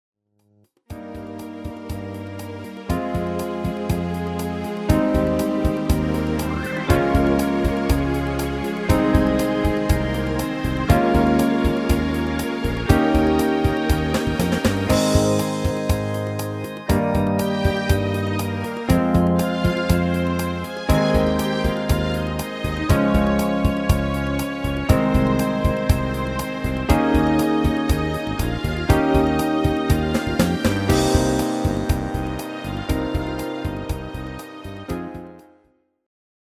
Demo/Koop midifile
Genre: R&B / Soul / Funk
- Géén vocal harmony tracks